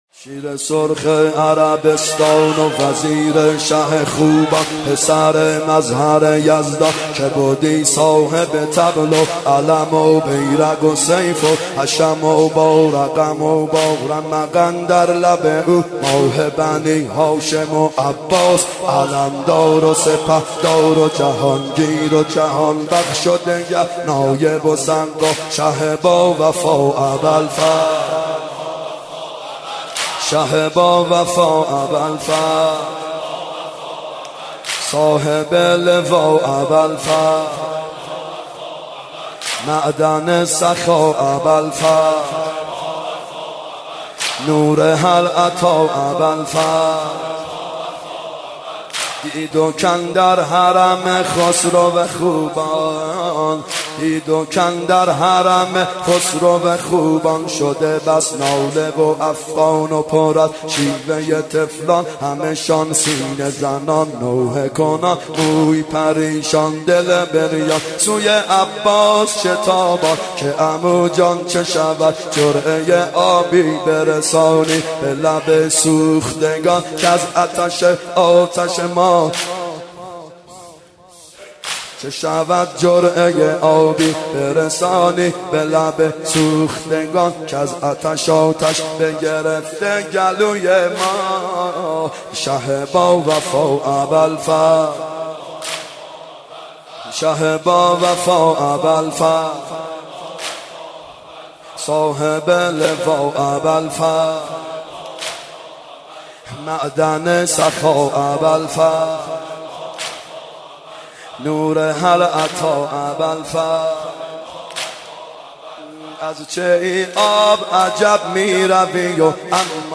محرم 92 شب نهم واحد ( شیر سرخ عربستان وزیر شه خوبان
محرم 92 ( هیأت یامهدی عج)